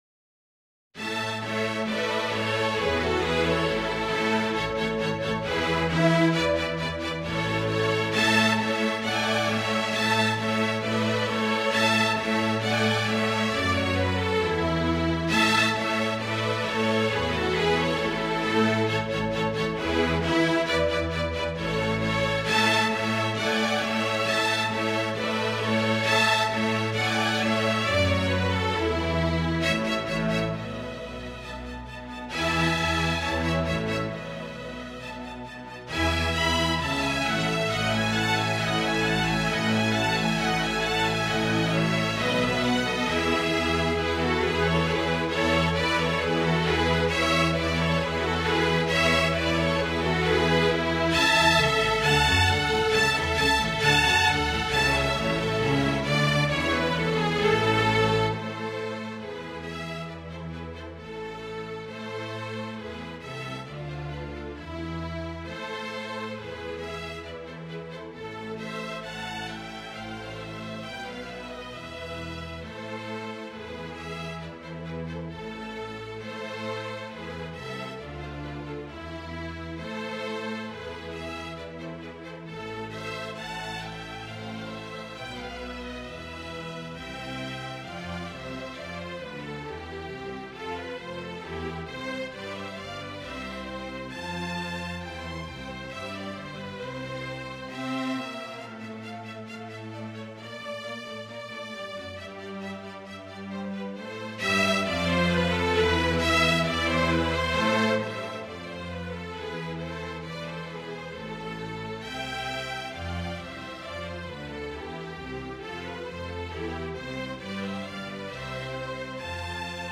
A scenario was published in the Mercure de France (June 1751: 2/160-66), and parts from the score (vln I, vln II and basso), written by Robert des Brosses (1719-1799), were also published.
The recordings are MIDI mockups, computer-generated using the playback software NotePerformer and performed as the music is written.
(MIDI mockup of no. 3 Première et Deuxième Gavottes)